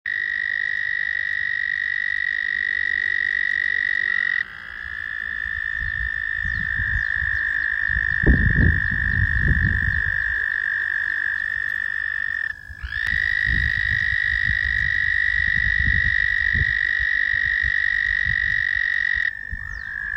The call of an American Toad consists of a lengthy trill that can last for 10 to 15 seconds.
American_toad.m4a